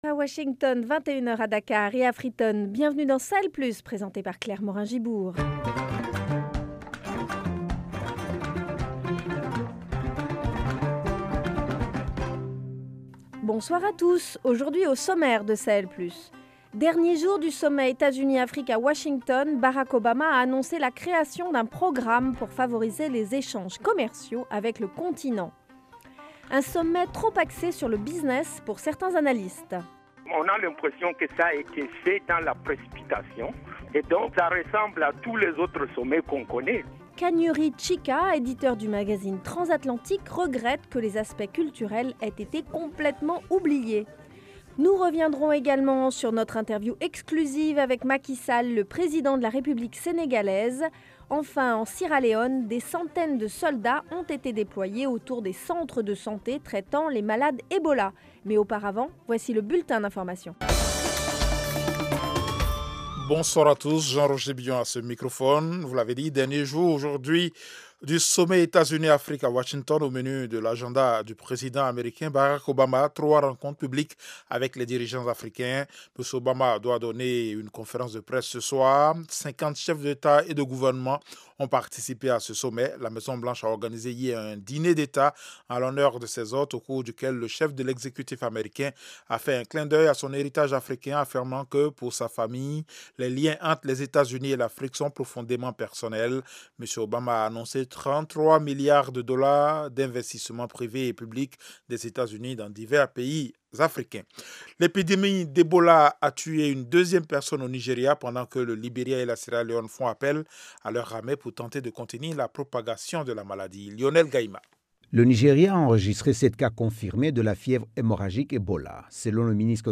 Au programme : bilan du sommet Etats-Unis- Afrique : réactions du ministre du commerce du Niger et du conseiller Energies du Tchad. Dernière partie l’interview avec Macky Sall, le président du Sénégal. Rubrique santé : au Mali, la région de Kayes reste une poche de résistance pour la lèpre.